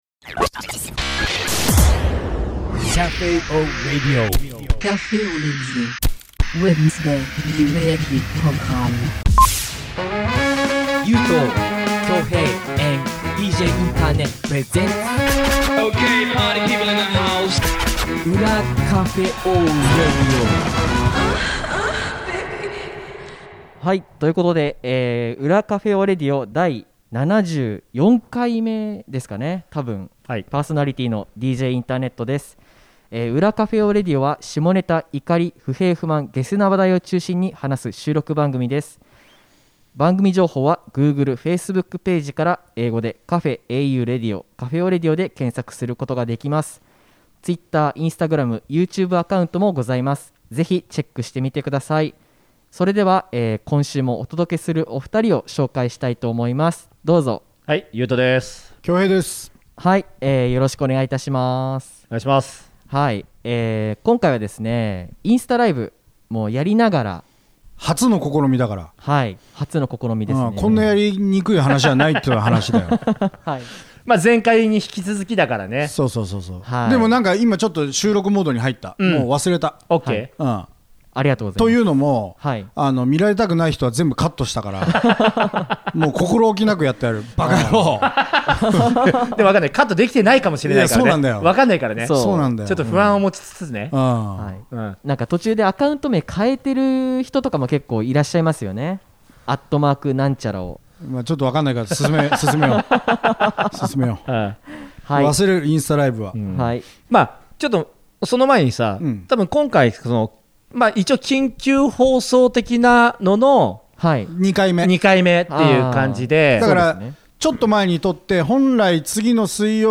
「ルーティーン」 今回は、先日実施したインスタライブトークの 後半の模様をお届けします！ リスナーさんから、皆さんはルーティーンを持っていますか？ というメッセージを頂きました。
験担ぎとは違う3人の生活を覗きました。 マスク着用しての収録なので 若干、声がこもりがちですがお楽しみ下さい！